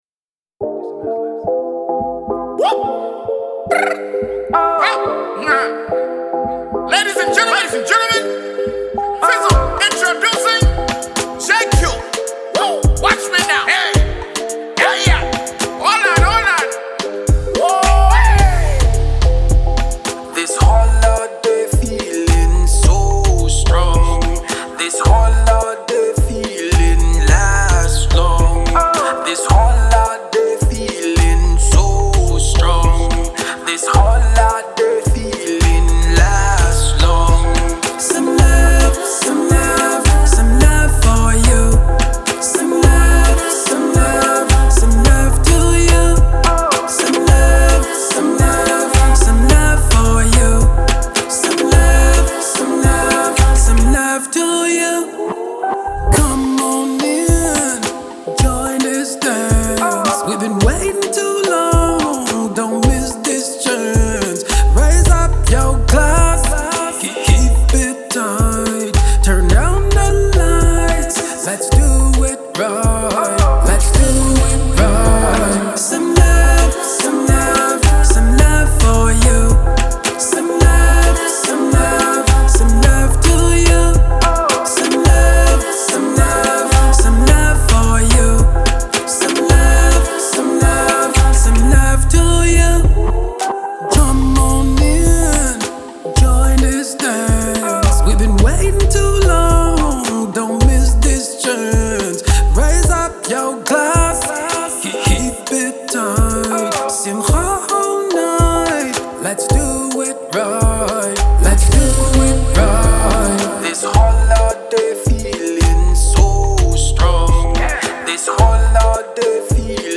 RnB
This catchy international party song